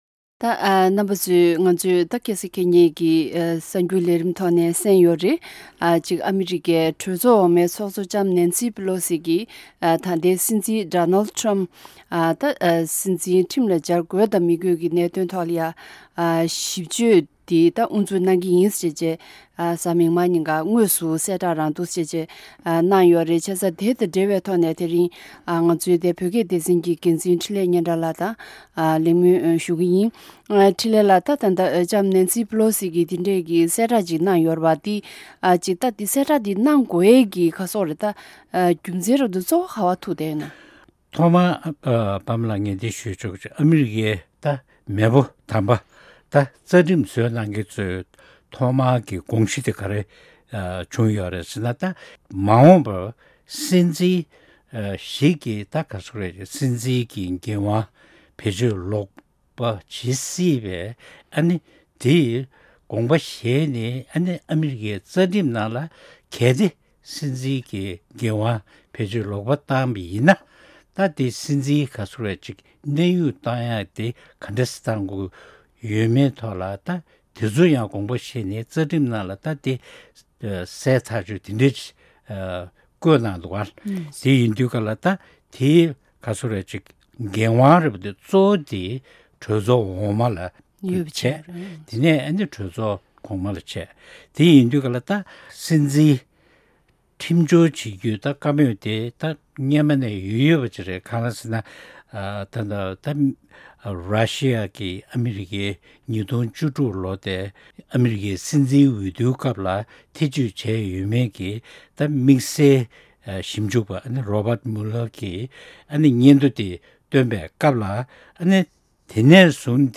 གླེང་མོལ་གནང་བའི་ལས་རིམ་དེ་གསན་རོགས་གནང་།